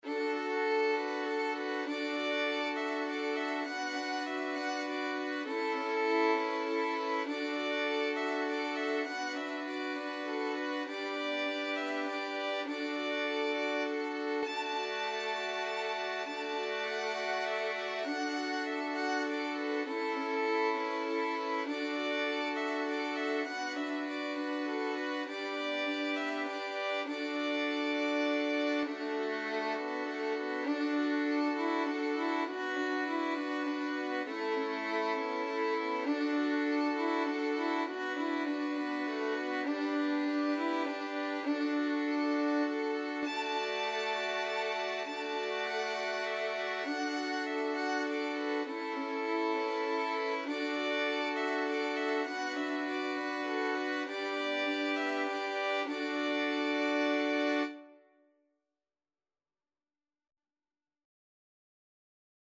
Free Sheet music for Violin Ensemble
"Arirang" is a Korean folk song, often considered as the unofficial national anthem of Korea.
D major (Sounding Pitch) (View more D major Music for Violin Ensemble )
3/4 (View more 3/4 Music)
Violin Ensemble  (View more Easy Violin Ensemble Music)
Traditional (View more Traditional Violin Ensemble Music)
world (View more world Violin Ensemble Music)
arirang_5VLN.mp3